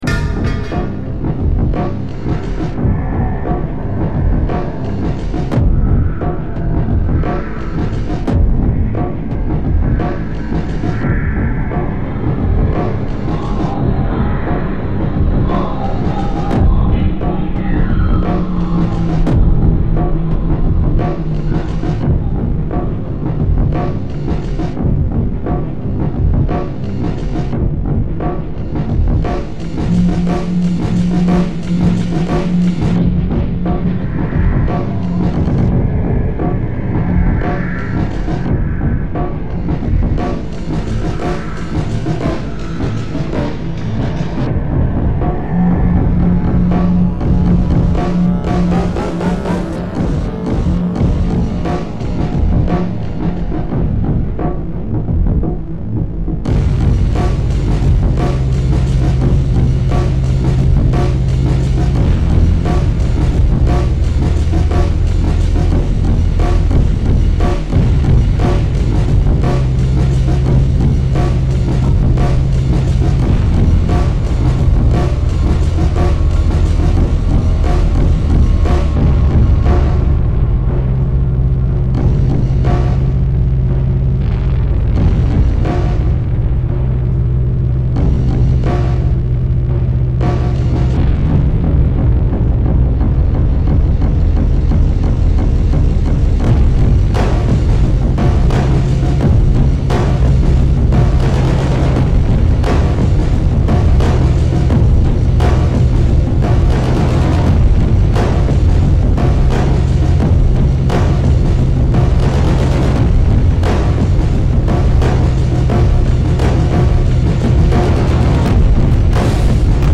Don't click if you don't like something that will wake the neighbors.
That works good tbh, some of it is a little oversampled, but the softer part at the beginning is about perfect for RTS music.